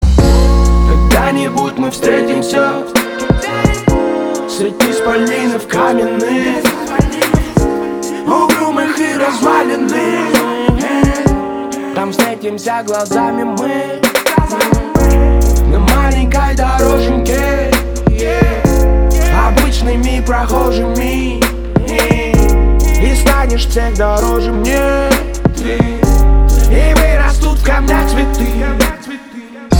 лирика
Хип-хоп
русский рэп
спокойные